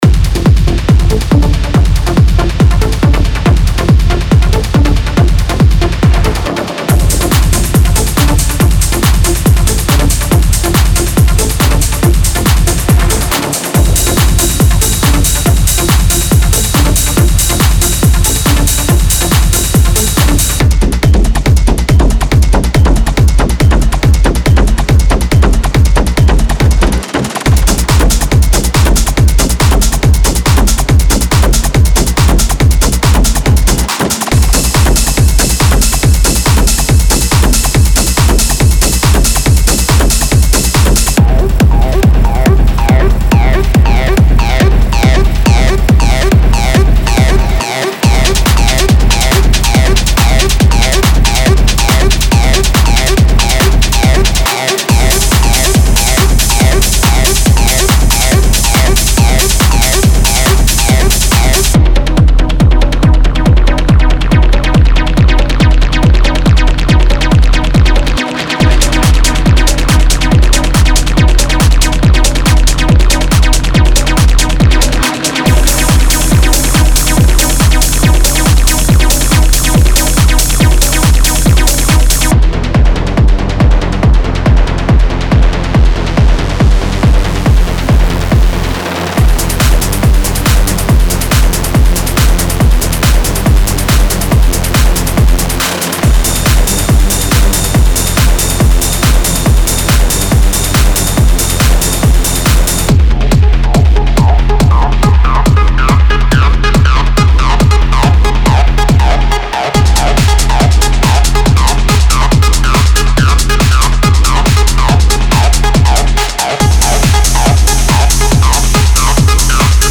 Genre:Techno
凶悪なベースライン、精巧なパーカッションレイヤー、雰囲気に満ちたサウンドスケープなどを求める方にとって、
デモサウンドはコチラ↓